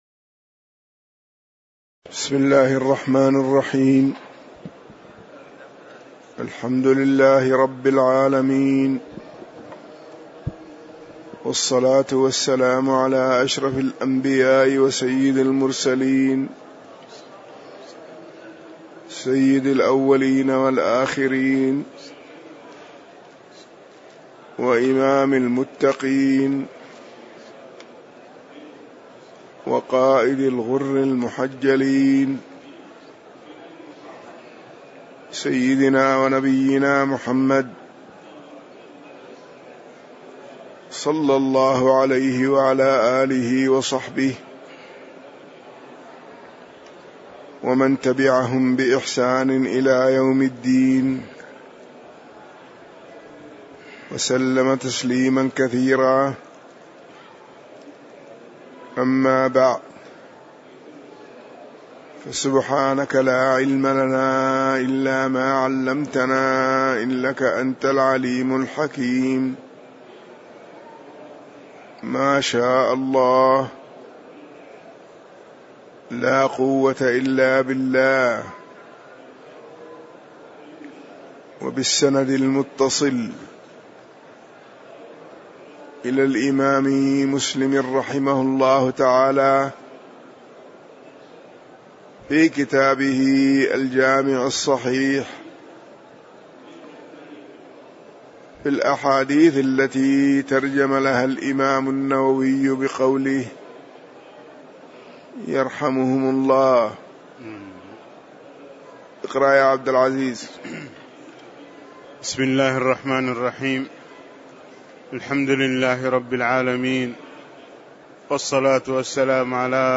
تاريخ النشر ١٧ ربيع الثاني ١٤٣٨ هـ المكان: المسجد النبوي الشيخ